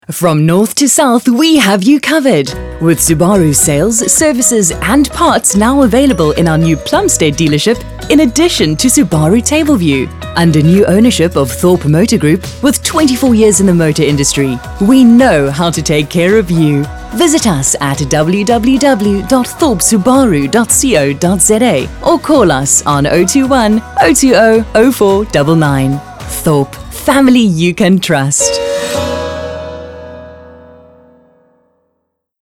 South Africa
authoritative, mature, seasoned, wise
My demo reels